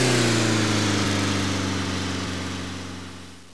1 channel
driveaway.wav